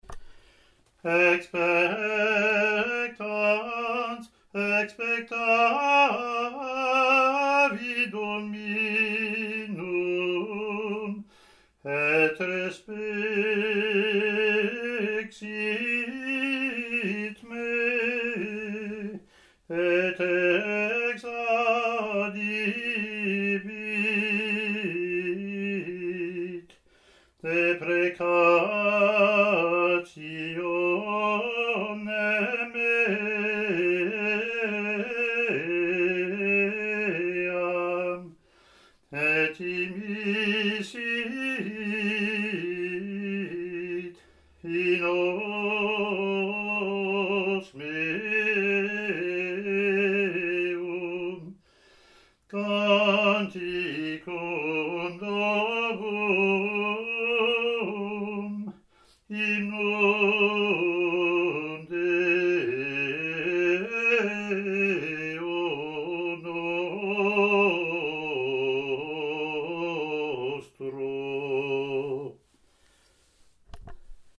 Latin antiphon )